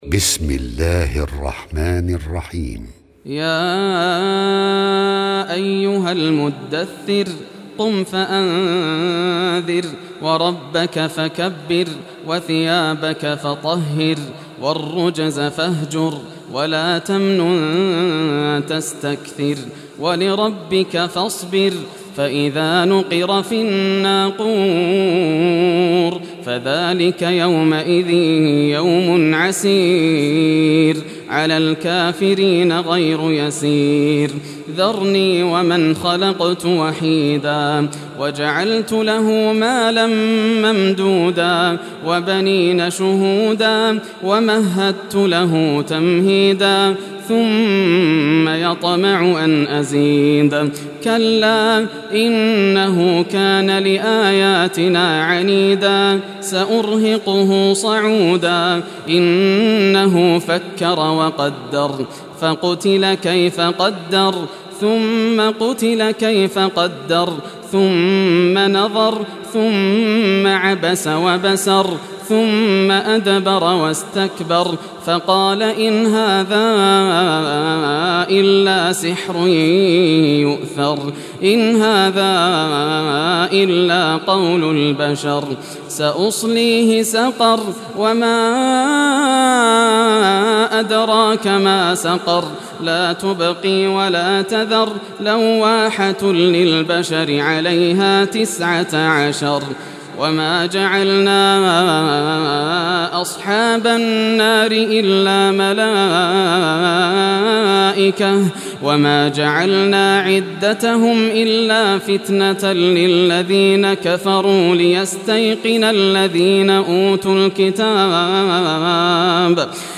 Surah Mudassir Recitation by Yasser al Dosari
Surah Mudassir, listen or play online mp3 tilawat / recitation in Arabic in the beautiful voice of Sheikh Yasser al Dosari.